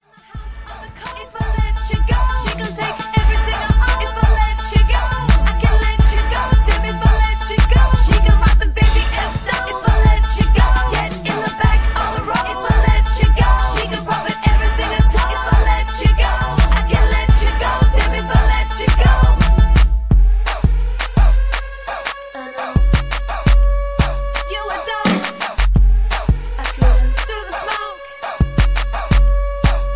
(Key-Am) Karaoke Mp3 Backing Tracks